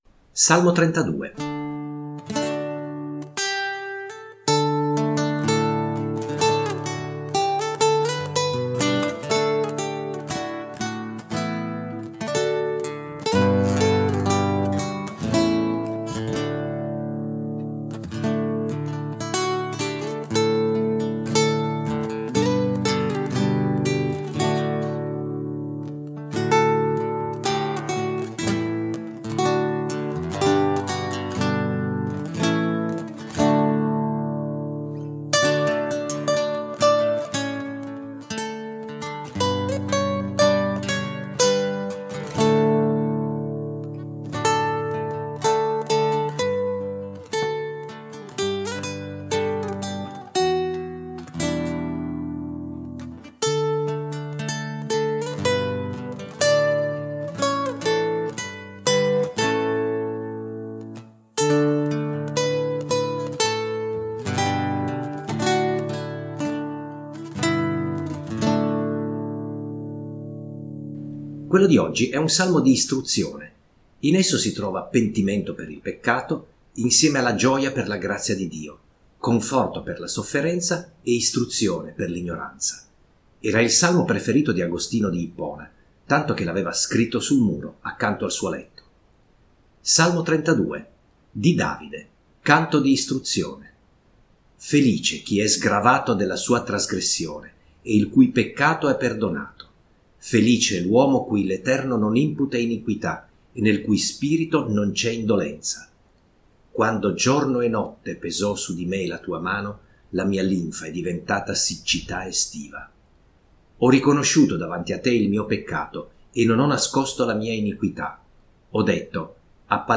Salmi cantati
con accompagnamento della chitarra